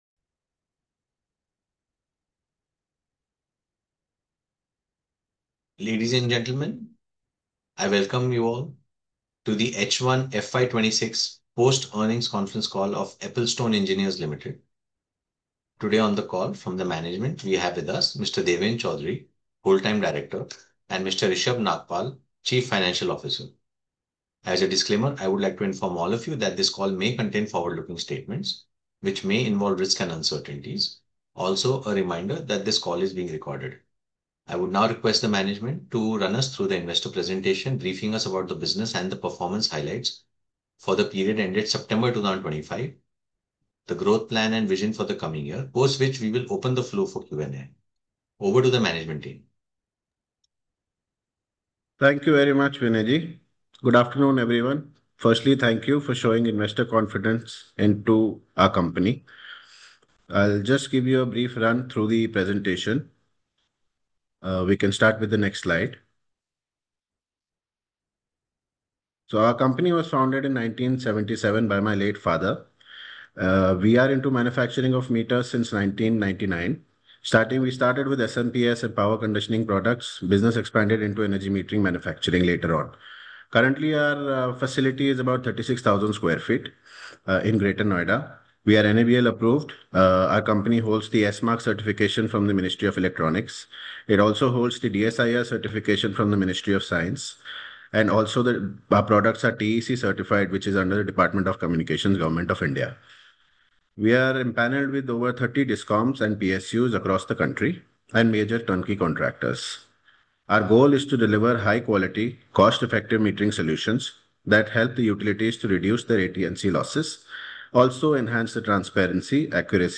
Post earnings conference call-audio-17.11.2025